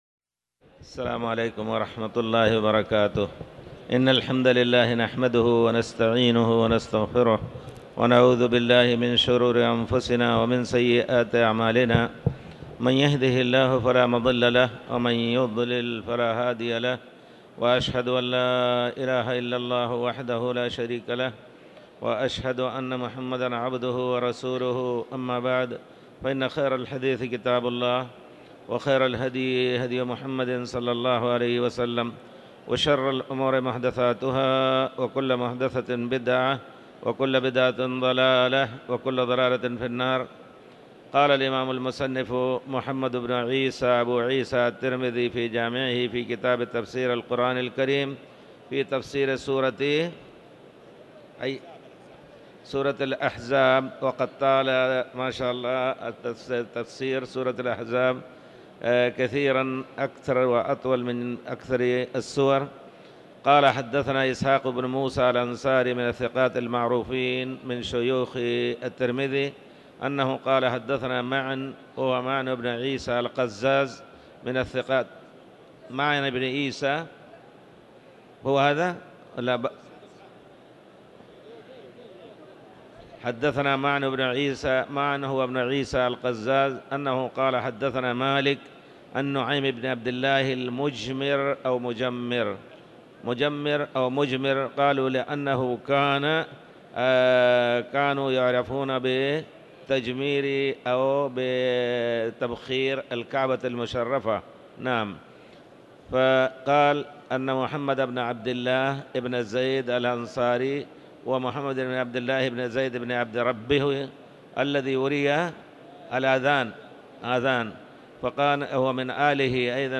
تاريخ النشر ٦ ربيع الثاني ١٤٤٠ هـ المكان: المسجد الحرام الشيخ